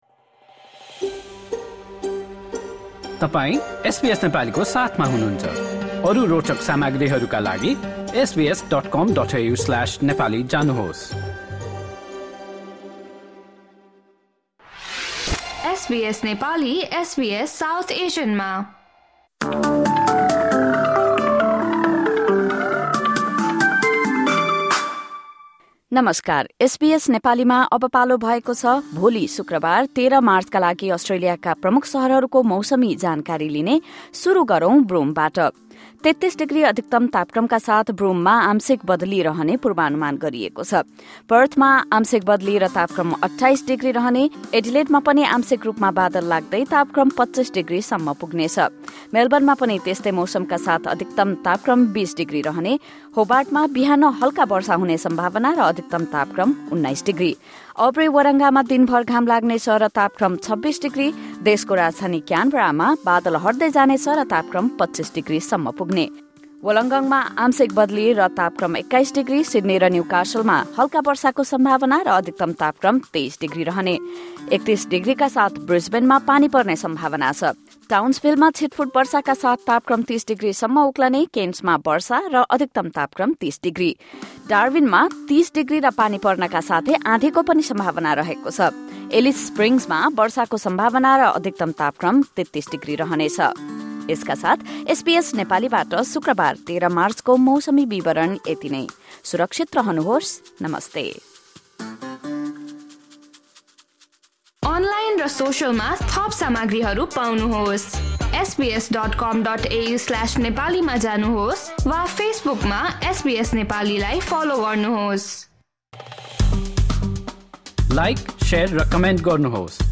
Weather update for major cities across Australia in Nepali. This update features tomorrow’s forecast for the following cities: Broome, Perth, Adelaide, Melbourne, Hobart, Albury-Wodonga, Sydney, Newcastle, Brisbane, Townsville, Cairns, Darwin and Alice Springs.